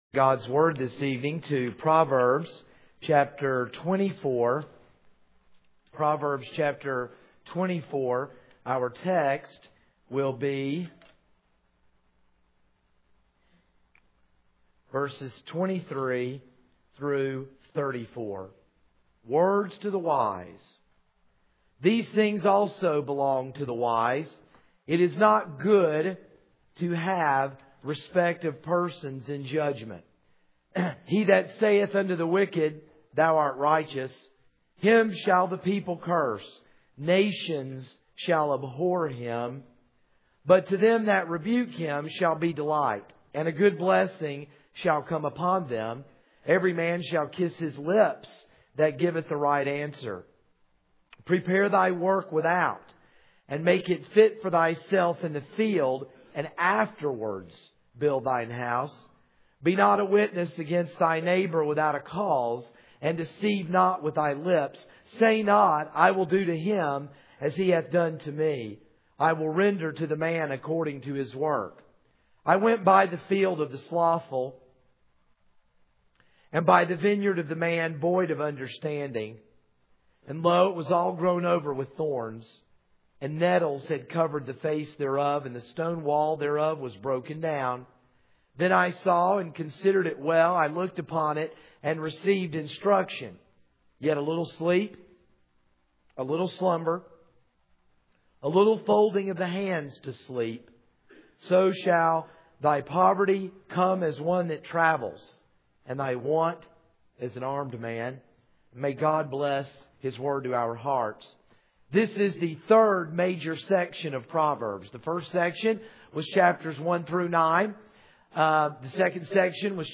This is a sermon on Proverbs 24:23-34.